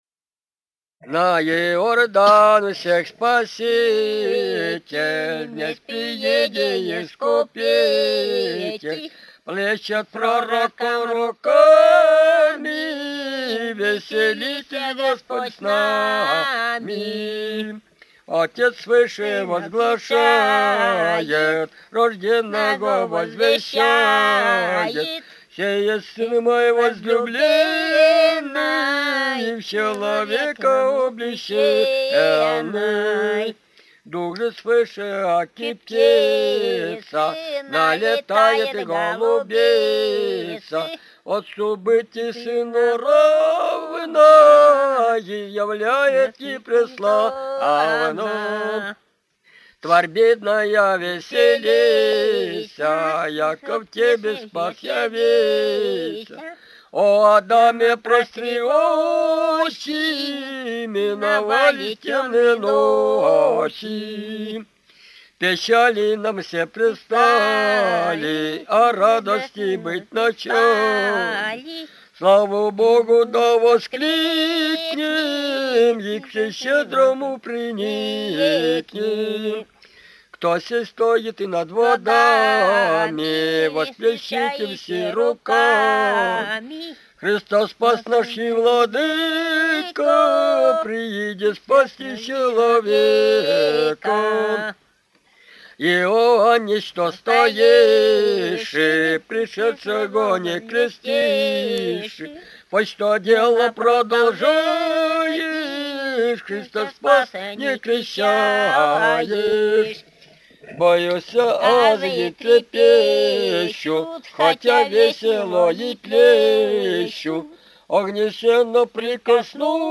Русские песни Алтайского Беловодья «На Иордан всех спаситель», духовный стих.
с. Тихонька Усть-Коксинского р-на, Горно-Алтайская АО, Алтайский край